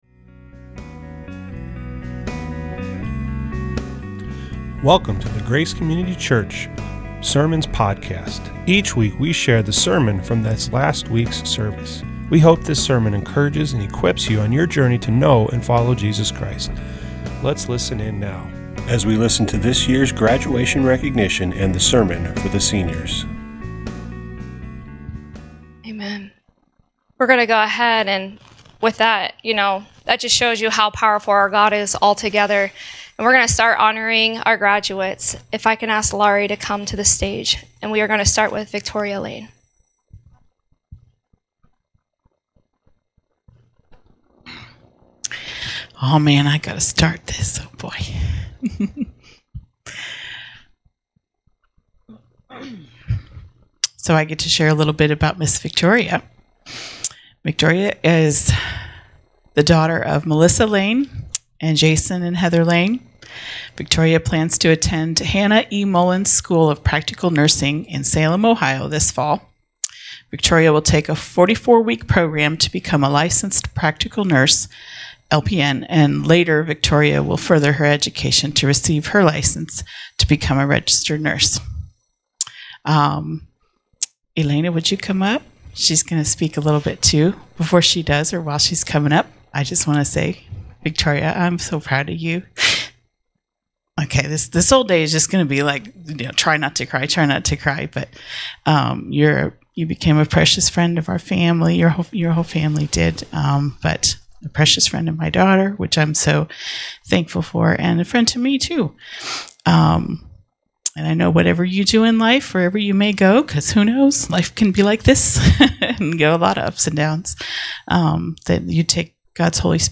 This week we honored our high school graduates!